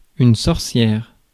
Ääntäminen
France (Paris): IPA: [yn sɔʁ.sjɛʁ]